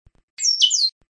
Bicudo - Entendendo o Canto
Canto Goiano
Ligação
Tí Ka Tí